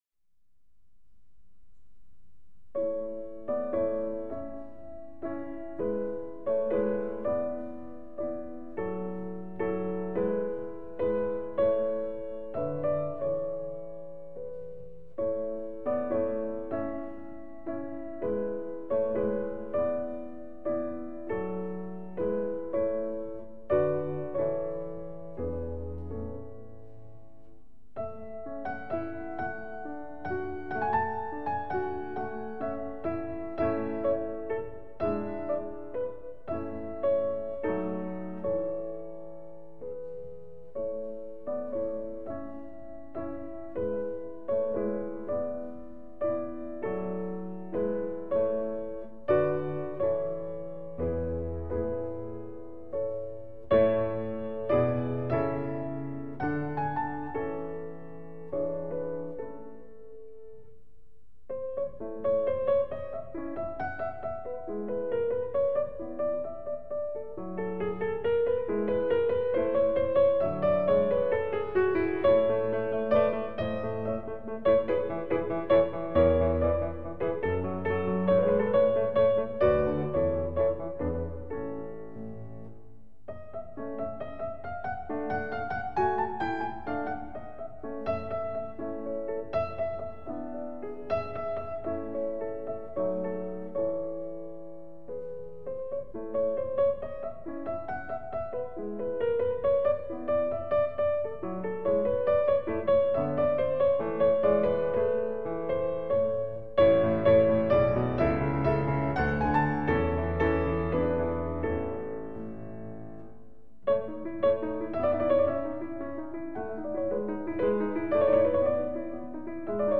in A-Dur (Kalvier